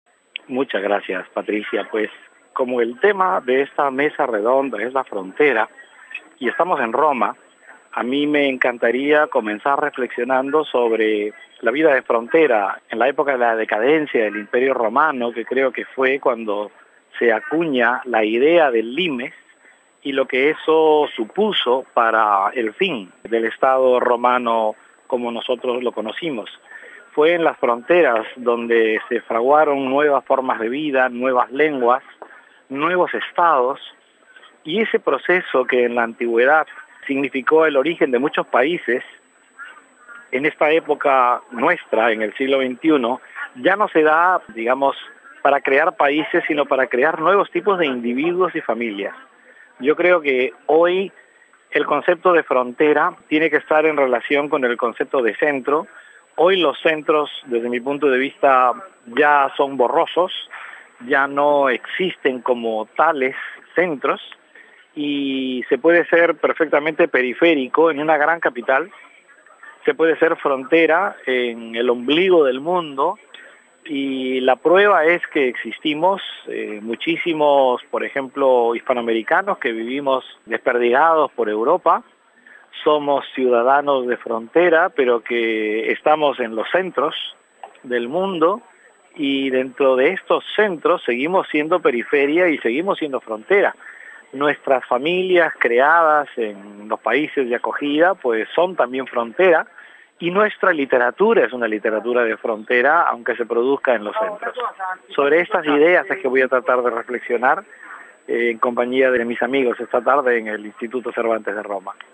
Hemos entrevistado a los dos últimos. Le preguntamos a Fernando Iwasaki sobre su ponencia en esta mesa redonda. audio RealAudio